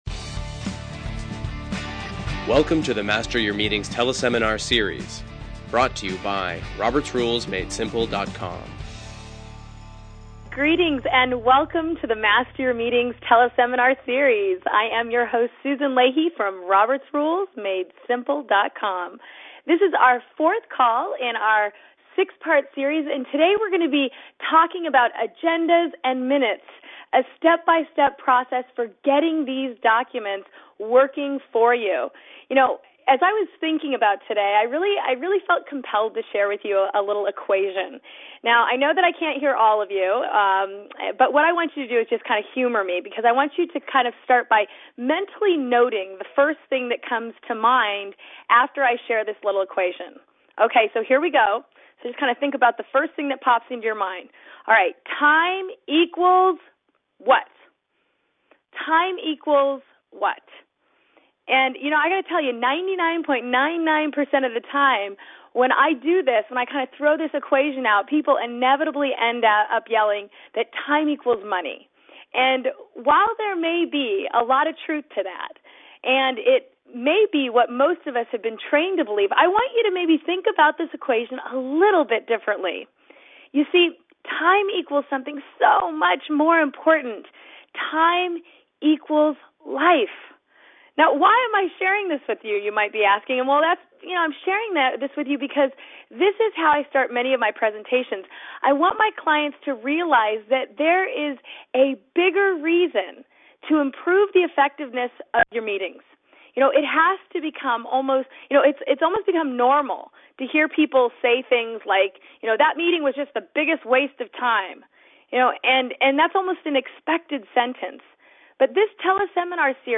Master Your Meetings Teleseminar Series Agendas & Minutes